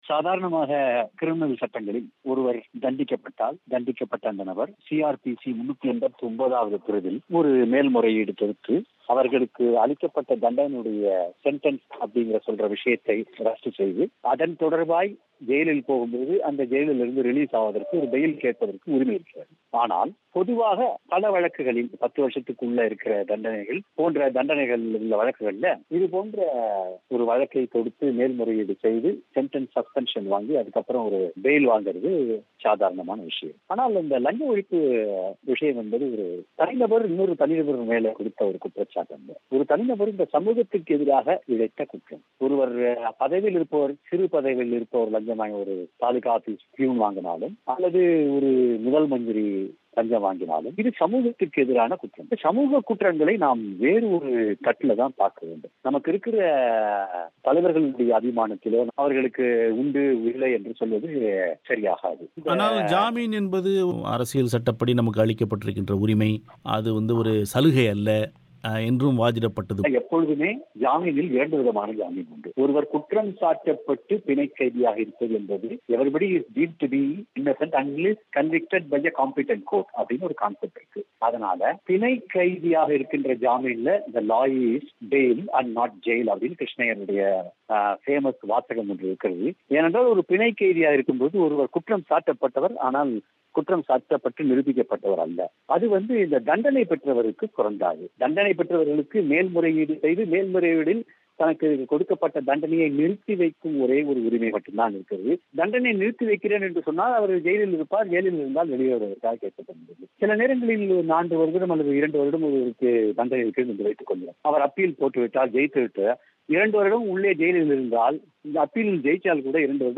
தமிழோசைக்கு வழங்கிய செவ்வி.